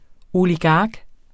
Udtale [ oliˈgɑːg ]